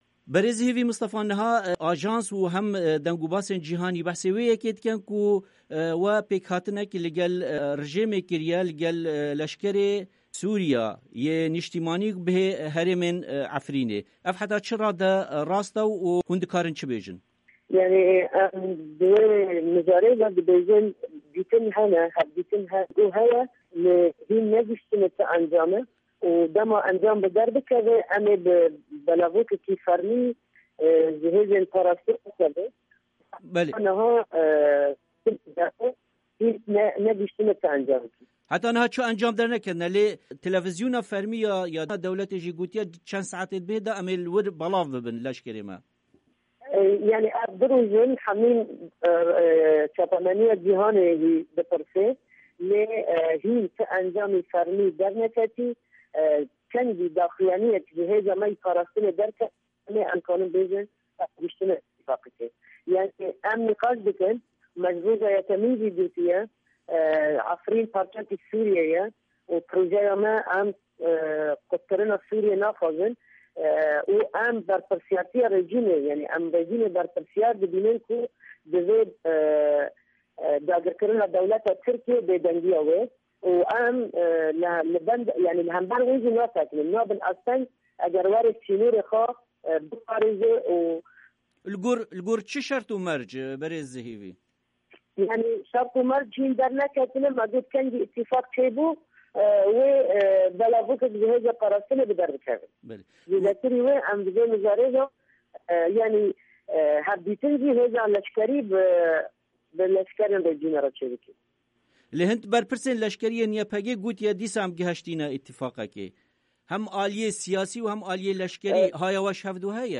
وتووێژ لەگەڵ هێڤی مستەفا